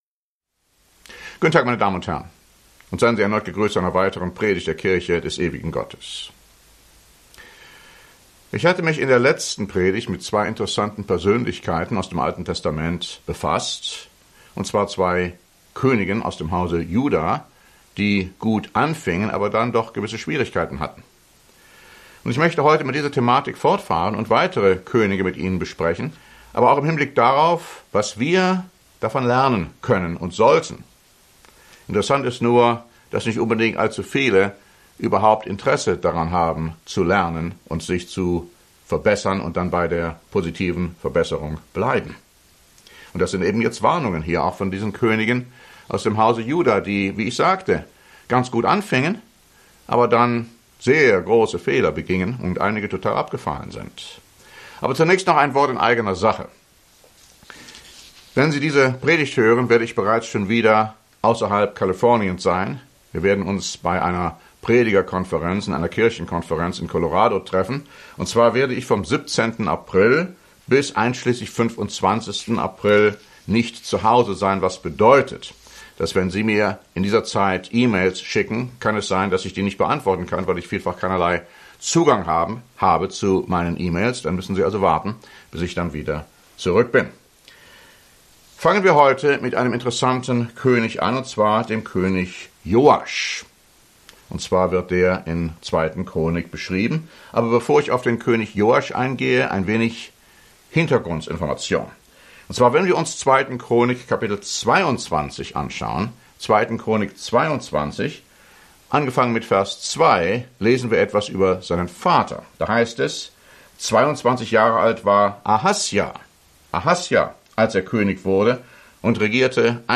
In dieser Predigt befassen wir uns mit den Königen von Juda–Joasch, Amazja, Usija und Hiskia–wie sie im 2. Buch Chronik beschrieben werden. In jedem Fall begingen diese gerechten Herrscher zu Ende ihres Lebens schwere Verfehlungen, und reagierten oftmals mit Hochmut, Ablehnung und sogar Zorn, wenn man sie auf ihre Sünden hinwies.